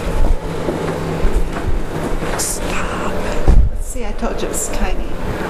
EVP Clip 2 Villisca Axe Murder House EVP Clip 2 This incredible EVP voice was recorded as two of our investigators were simply walking around the house checking it out as we set up. They were either on their way upstairs or already there when this loud voice, unheard by them, was recorded. This one is amazingly clear - and very aggressive! Entity gives stern warning to investigators A very loud whisper voice says, "STOP!" show/hide spoiler Back to Villisca Axe Murder House Evidence Page